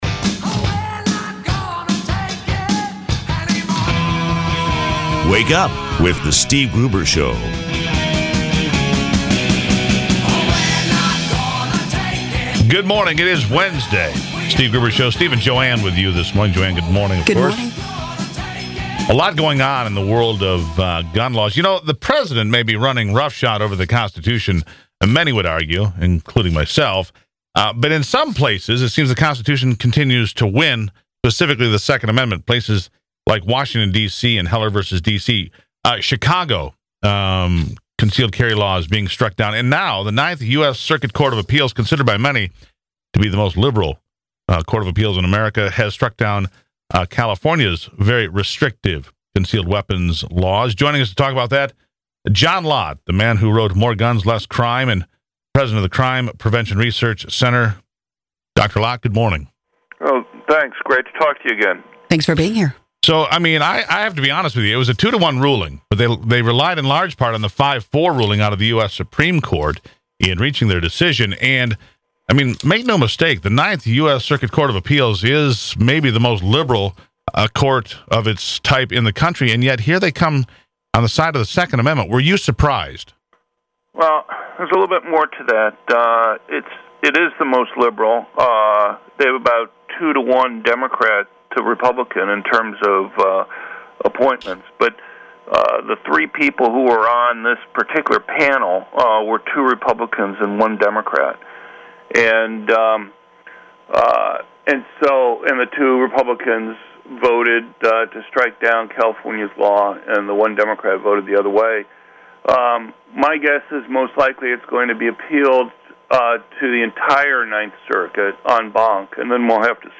Interview on Michigan Talk Radio about the 9th Circuit decision on Concealed Carry